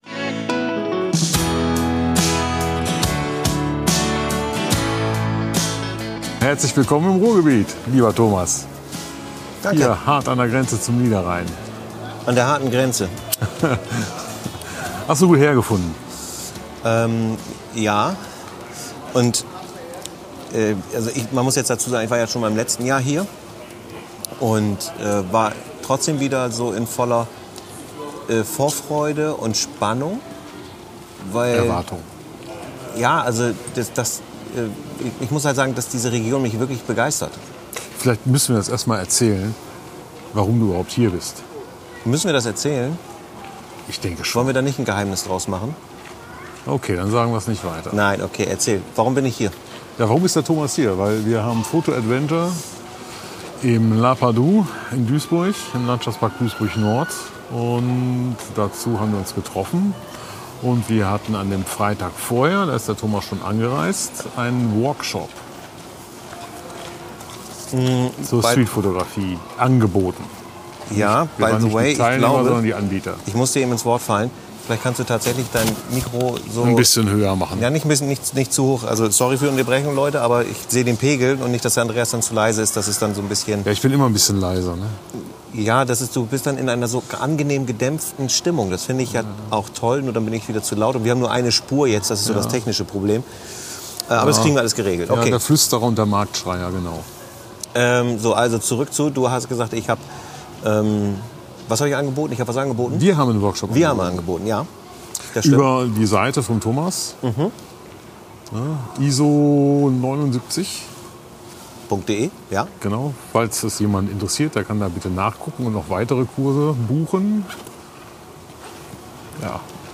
Folge 39 - Live von der Photo+Adventure & Kommt nun das Tattoo oder nicht..??